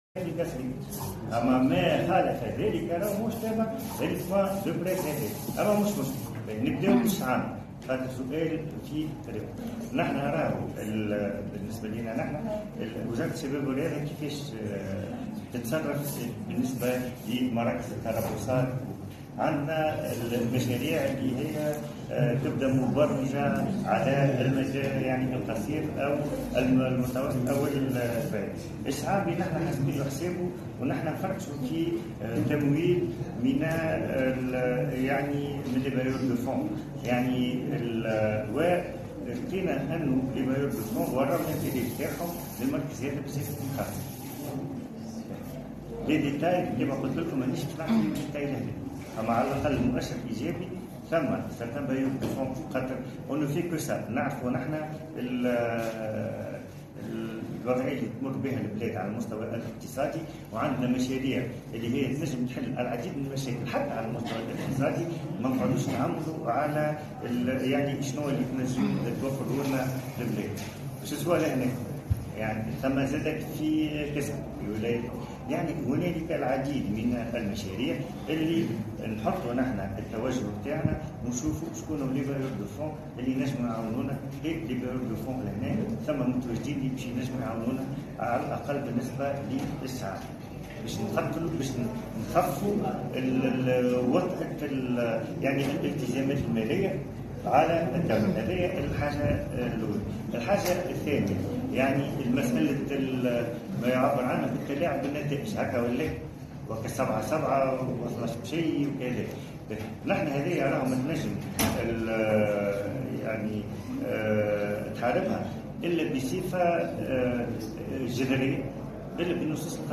مقطف من كلمة الوزير خلال الندوة الصحفية المنعقدة بمقر المركب الشبابي و الرياضي بالقصرين: